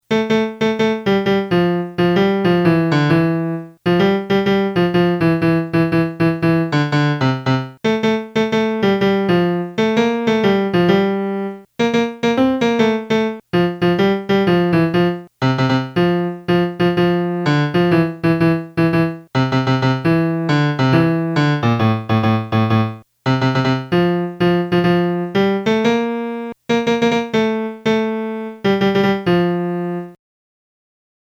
ghe-tante-signorine-melody.mp3